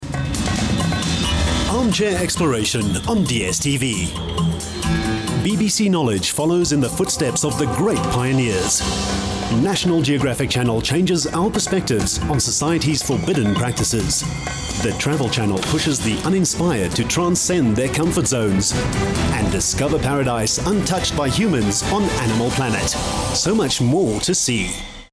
South African, SA English, Afrikaans, smooth, clear, crisp, retail, character, professional voice over, corporate, hard sell, soft sell, in house, gaming, documentary, conversational
Sprechprobe: eLearning (Muttersprache):